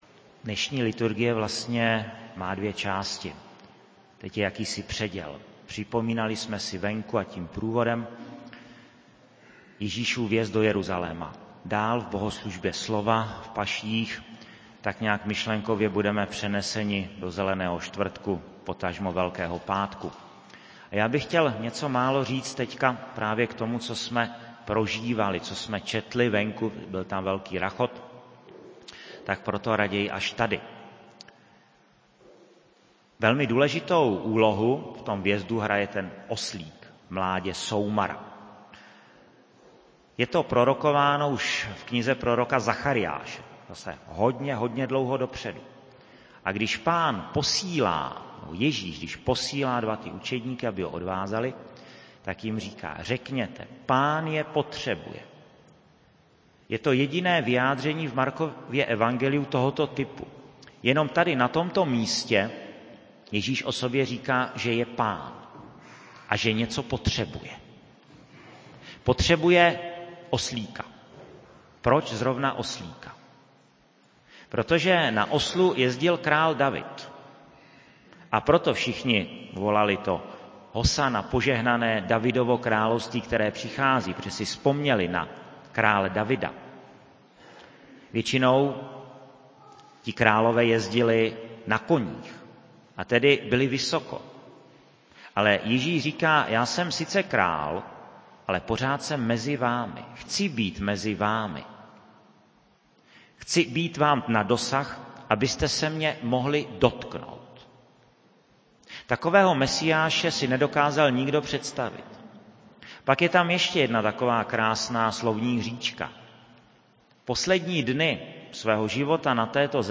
Rubrika Homilie
29.03.2015, kostel sv. Jakuba ve Veverské Bítýšce
KVĚTNÁ NEDĚLE
[MP3, mono, 16 kHz, VBR 21 kb/s, 1.08 MB]